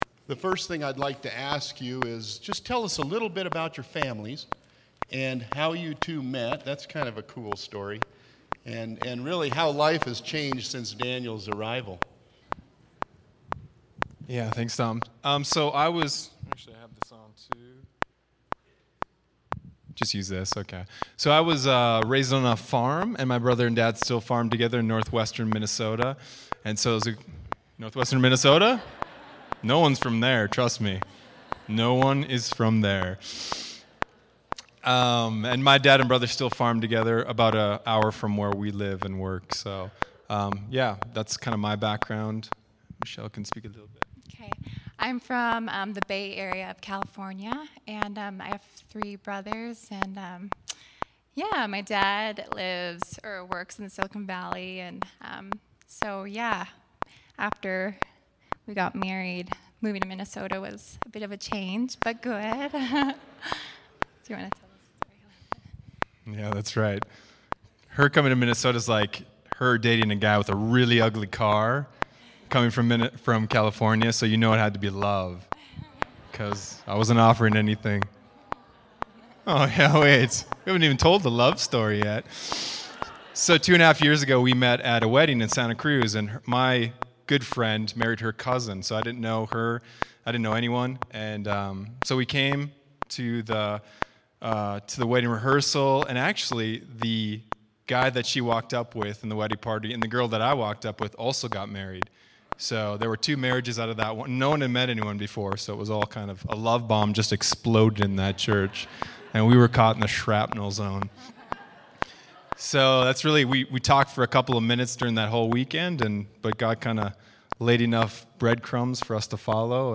Pastoral Candidate Weekend
sermon-March-4.mp3